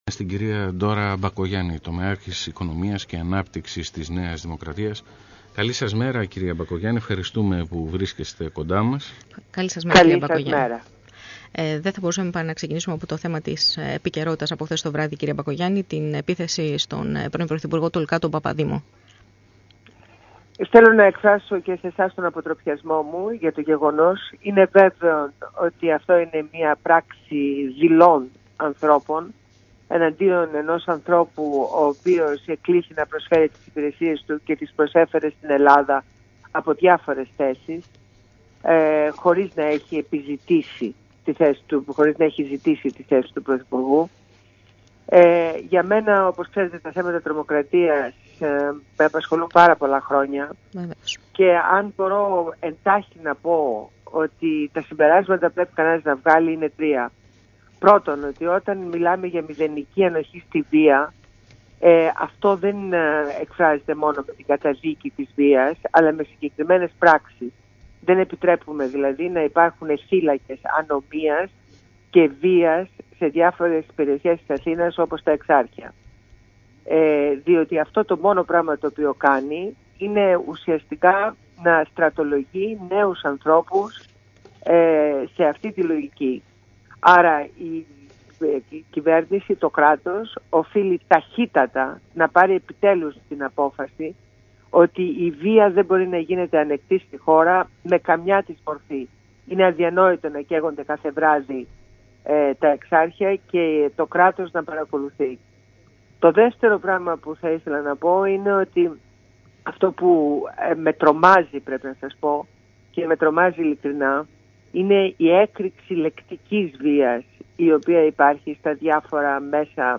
Συνέντευξη στο Πρακτορείο fm ΑΠΕ - ΜΠΕ Θεσσαλονίκης
Ακούστε τη συνέντευξη στο ραδιόφωνο Πρακτορείο fm ΑΠΕ – ΜΠΕ Θεσσαλονίκης